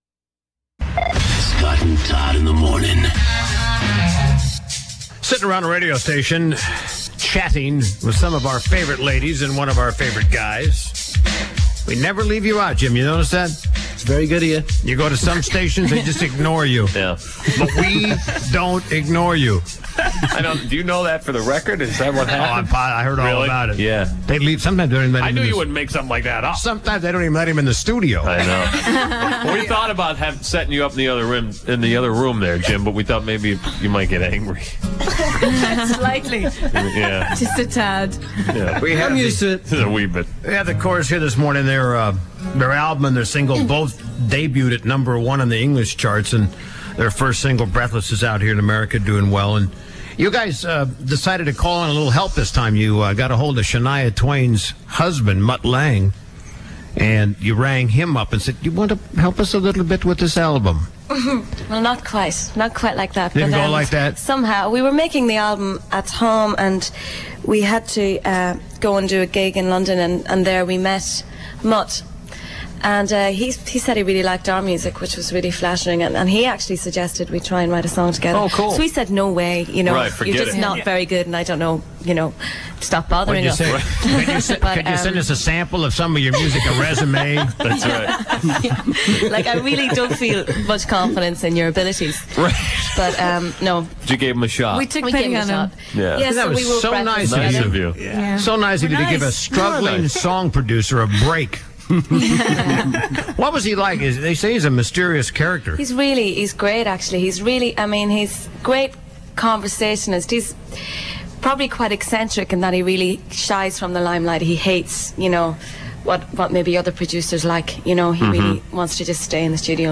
WPLJ Interview-Part 2